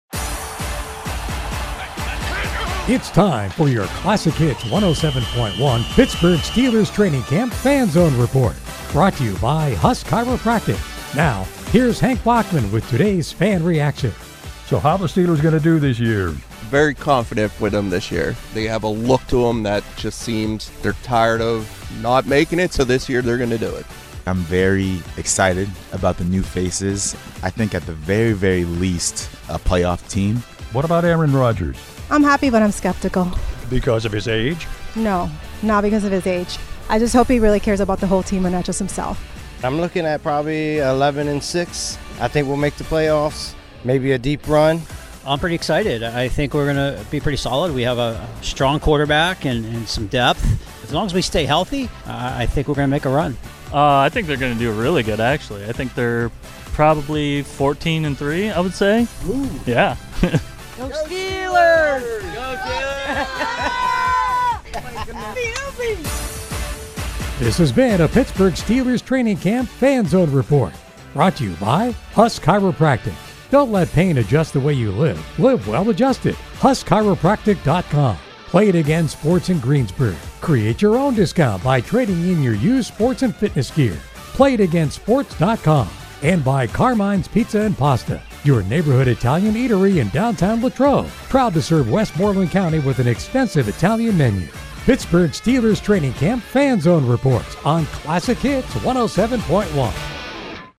Our Greensburg affiliate WHJB is at Steelers Training Camp in Latrobe and filing three reports daily on the day’s activities and more.